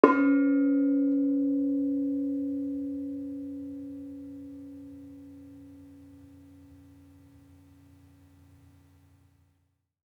Bonang-C#3-f.wav